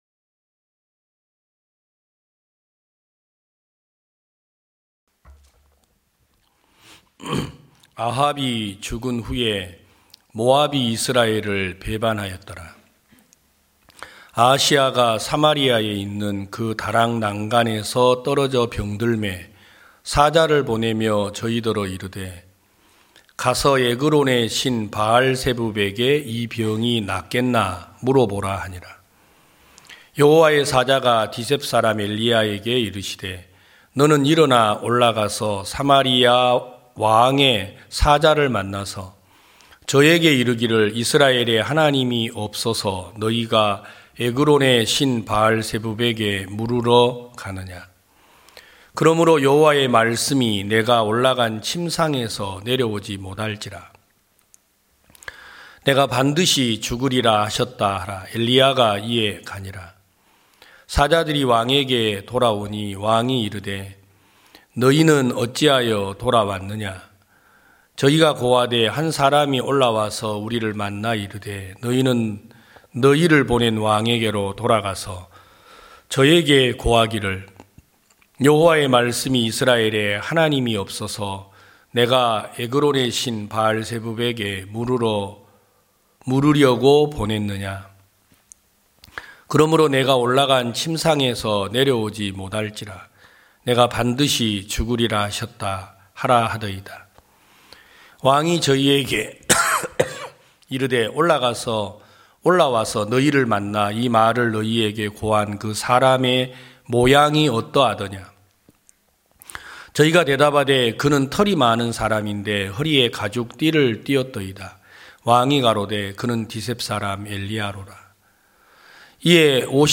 2023년 1월 22일 기쁜소식부산대연교회 주일오전예배
성도들이 모두 교회에 모여 말씀을 듣는 주일 예배의 설교는, 한 주간 우리 마음을 채웠던 생각을 내려두고 하나님의 말씀으로 가득 채우는 시간입니다.